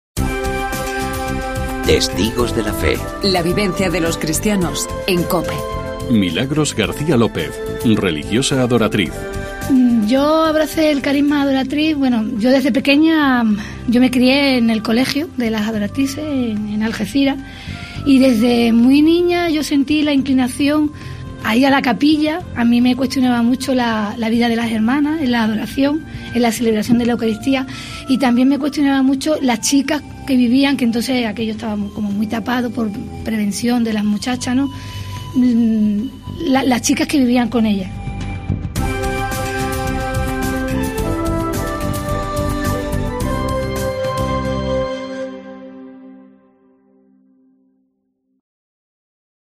Escuchamos el testimonio de la adoratriz